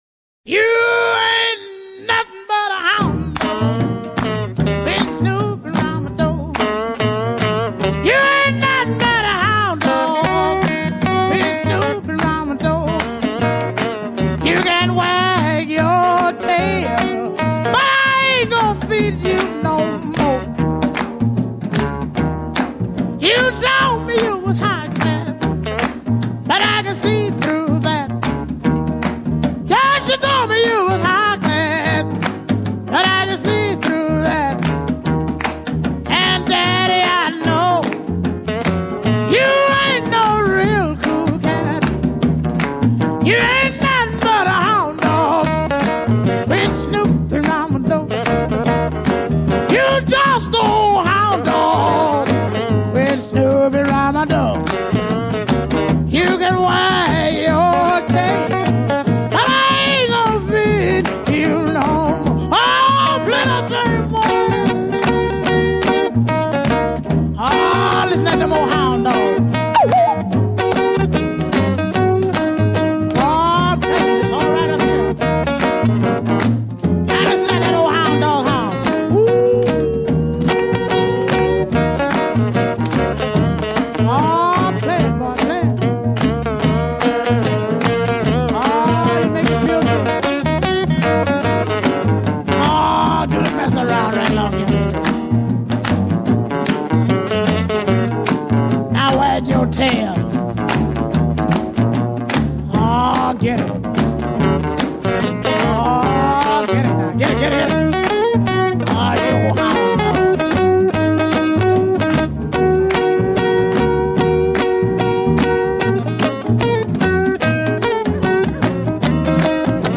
bluesy